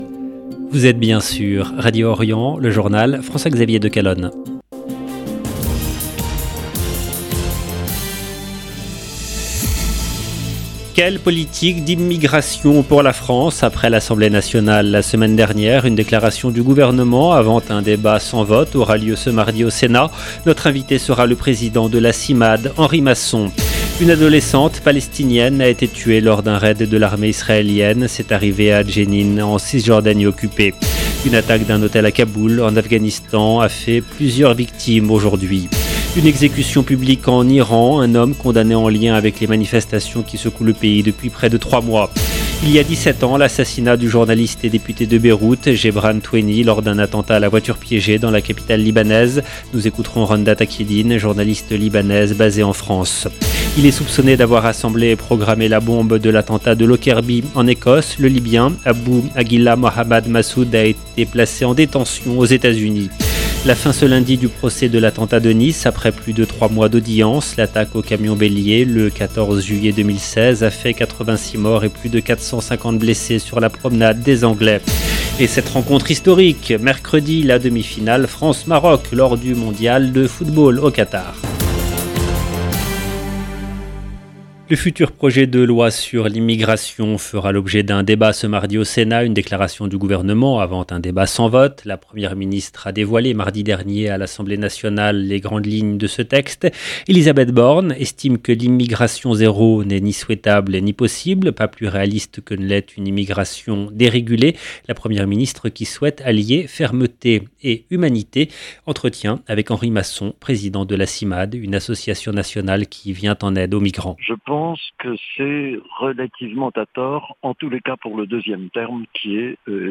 LE JOURNAL DU SOIR EN LANGUE FRANCAISE DU 12/12/22